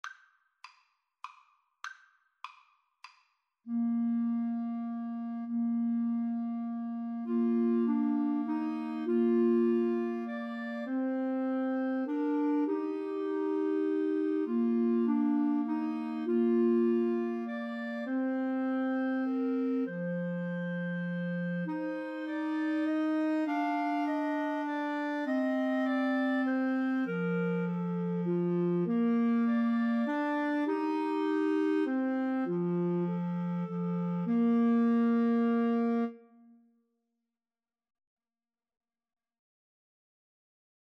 Bb major (Sounding Pitch) C major (Clarinet in Bb) (View more Bb major Music for Clarinet Trio )
Moderato
3/4 (View more 3/4 Music)
Clarinet Trio  (View more Easy Clarinet Trio Music)